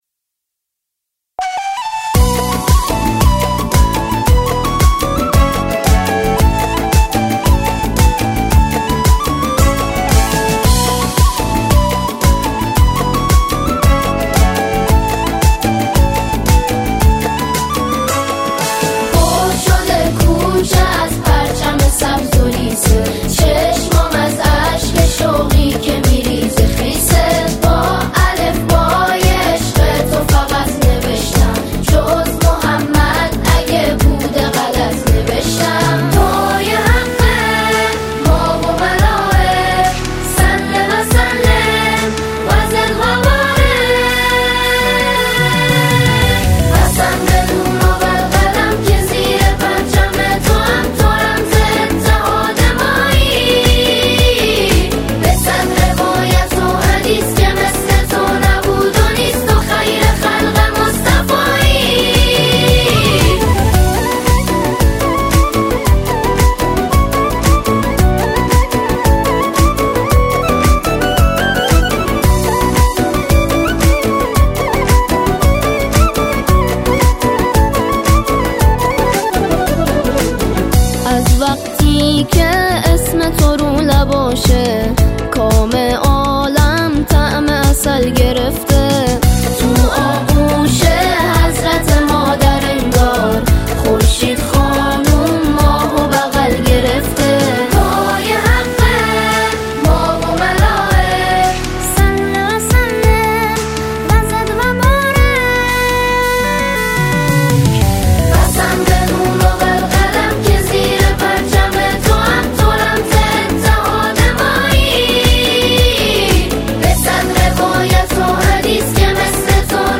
نسخه با تکخوان